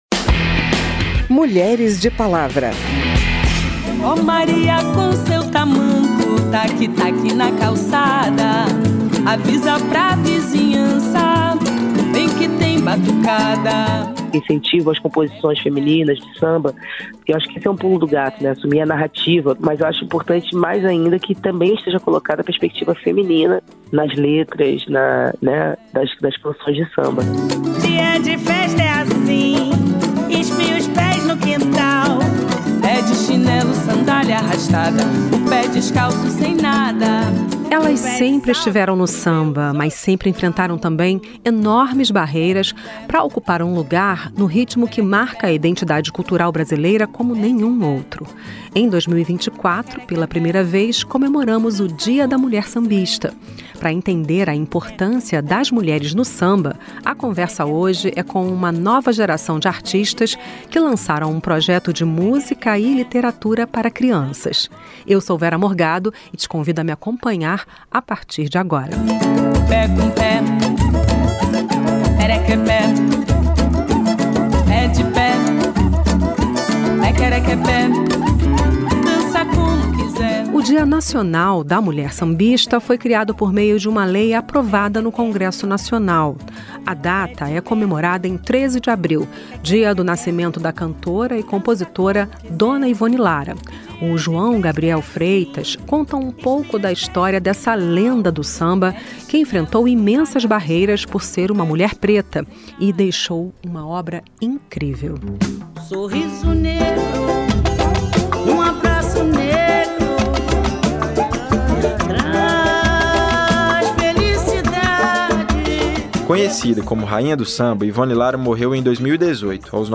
Pra entender a importância das mulheres no samba, a conversa é com uma nova geração de artistas que lançaram um projeto de música e literatura para crianças.